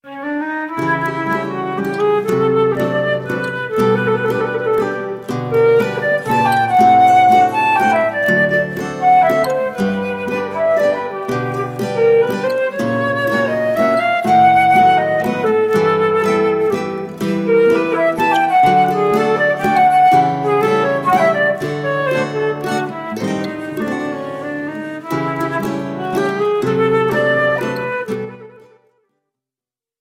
flute
Choro ensemble